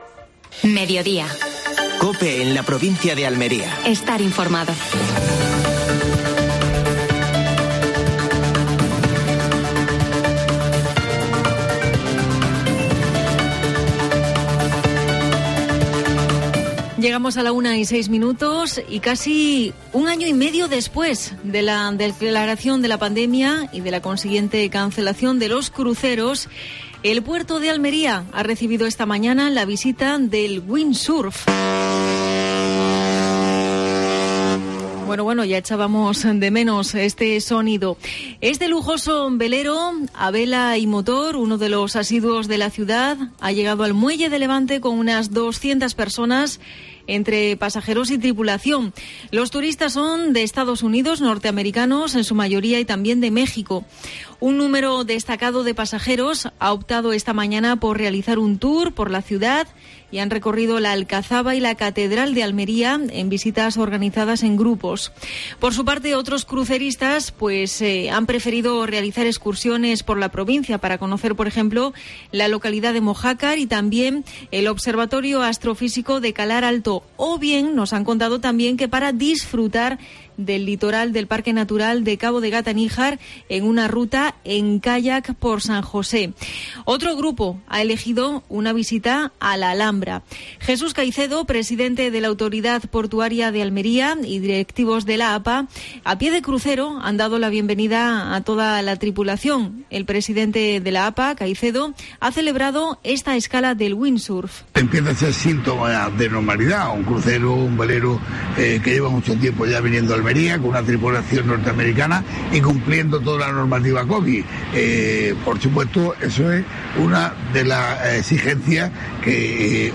Entrevista a Diego Cruz (concejal de Cultura del Ayuntamiento de Almería).